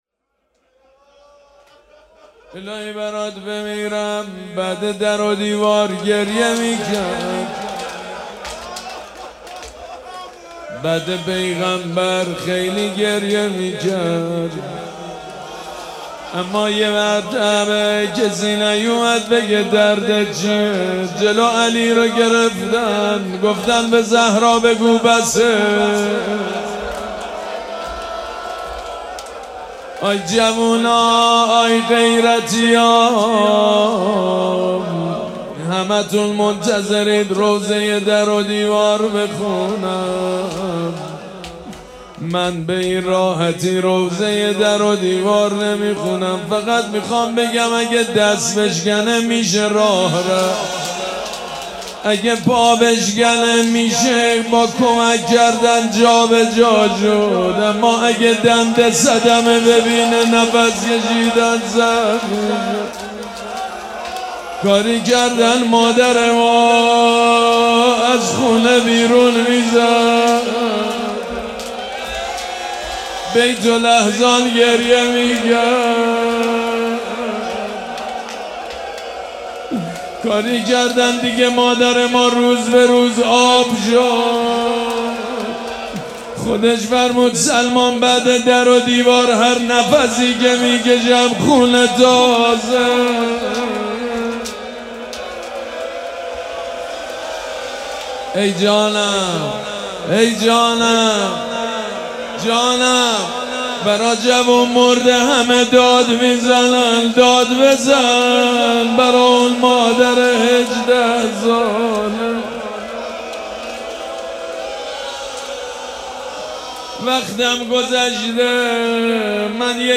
شب اول مراسم عزاداری دهه دوم فاطمیه ۱۴۴۶
حسینیه ریحانه الحسین سلام الله علیها
روضه